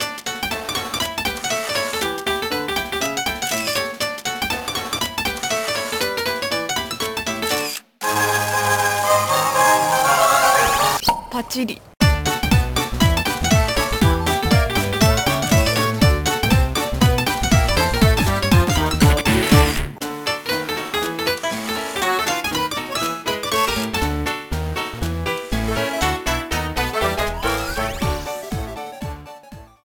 Title music